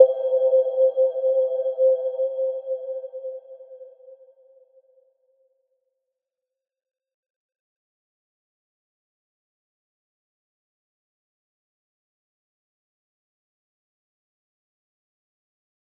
Little-Pluck-C5-mf.wav